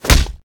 combat / abilities / right hook / flesh3.ogg
flesh3.ogg